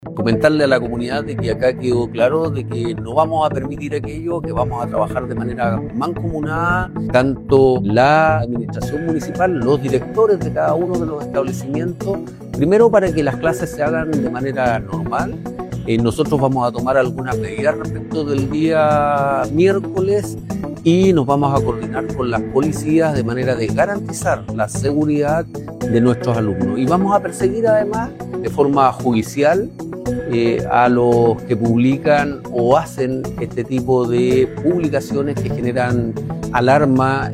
El alcalde de San Fernando Pablo Silva Pérez explicó que esta acción responde a la reciente ola de amenazas que ha afectado a distintos colegios en la región durante los últimos días.